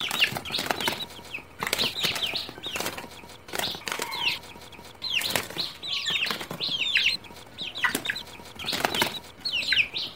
Tiếng Gà con Đá nhau, Chọi nhau, Giao chiến…
Tiếng Gà vỗ cánh phành phạch và Gáy… Tiếng Gà Chọi Đá nhau, Tung đòn, Lên đòn…
Thể loại: Tiếng vật nuôi
Âm thanh gầm rú từ việc đá lẻ, cánh đập nhau, và tiếng gà gáy hòi họa tạo nên bầu không khí căng thẳng.
tieng-ga-con-da-nhau-choi-nhau-giao-chien-www_tiengdong_com.mp3